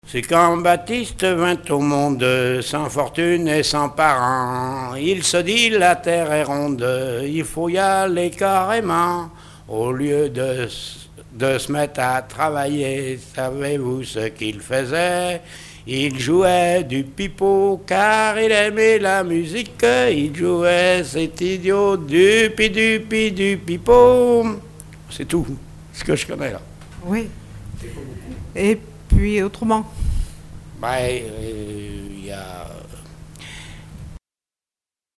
musique varieté, musichall
chanons populaires
Pièce musicale inédite